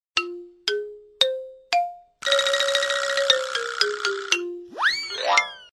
알림음 8_멍때리는소리.mp3